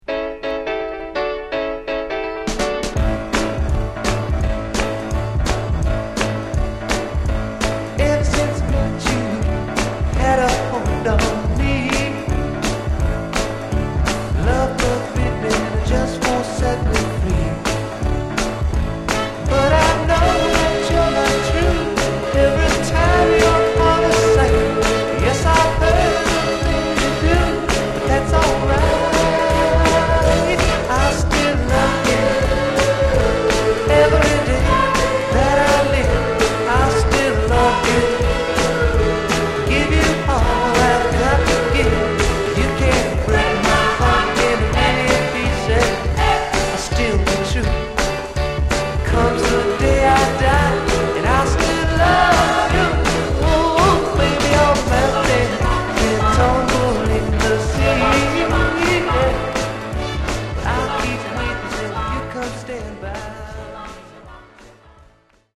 Genre: Other Northern Soul
note-perfect Motown-style soul record